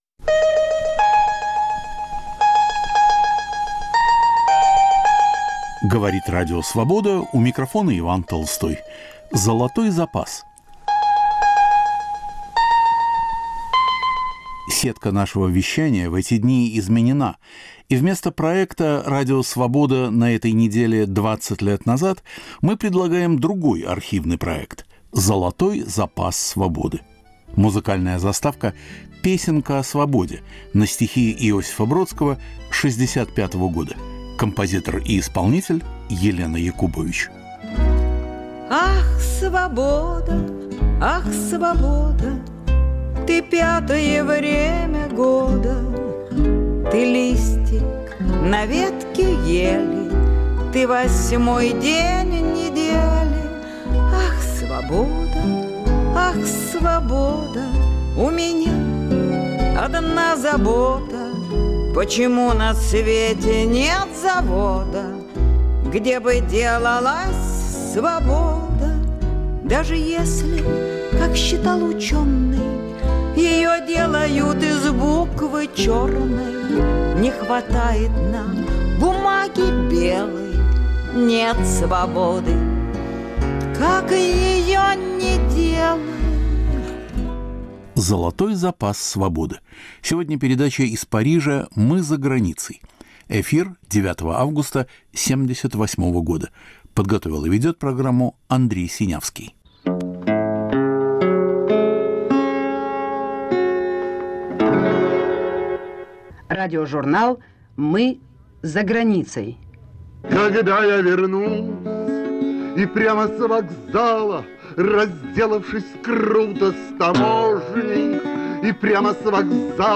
Передача из Парижа "Мы за границей".
Ведет Андрей Синявский. Студенческий театральный спектакль по "Реквиему" Анны Ахматовой.